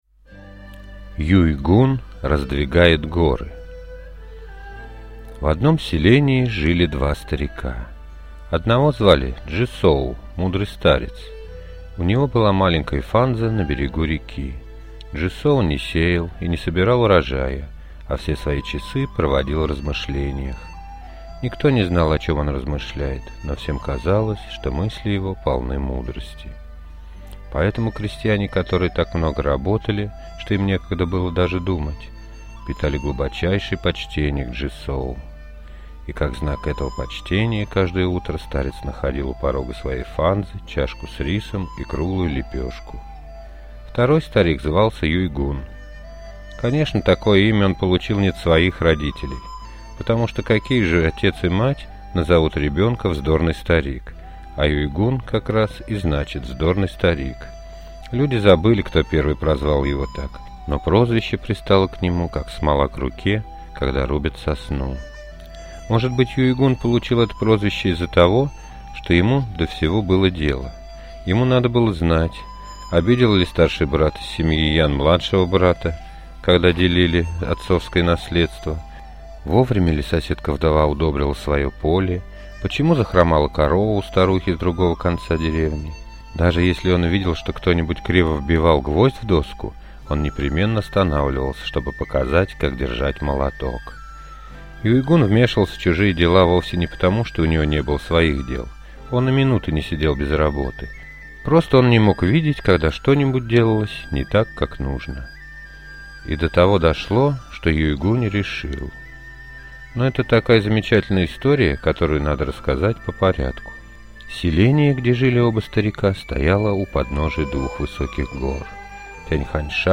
Юй-гун раздвигает горы – китайская аудиосказка